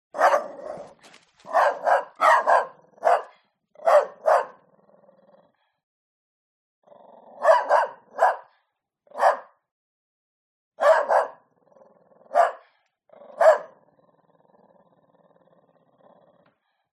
دانلود آهنگ سگ فانتزی از افکت صوتی انسان و موجودات زنده
دانلود صدای سگ فانتزی از ساعد نیوز با لینک مستقیم و کیفیت بالا
جلوه های صوتی